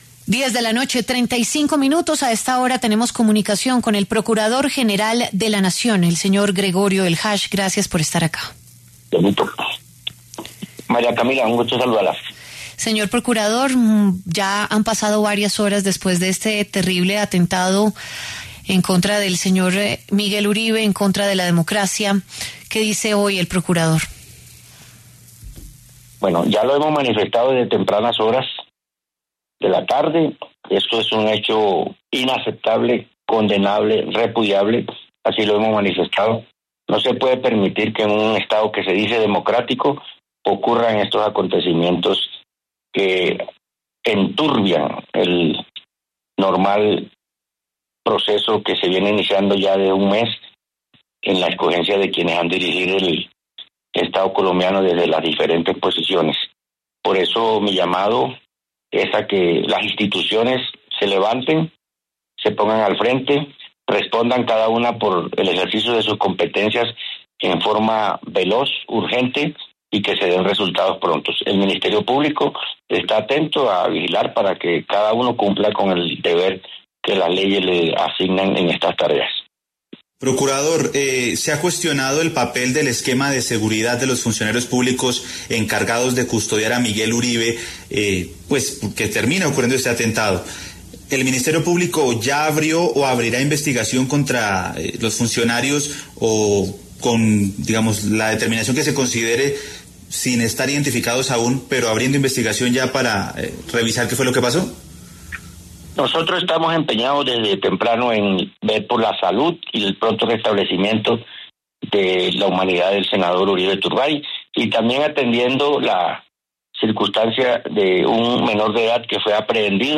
El procurador general de la Nación, Gregorio Eljach, conversó en los micrófonos de W Radio sobre el atentado contra el precandidato presidencial Miguel Uribe en la localidad de Fontibón, en Bogotá.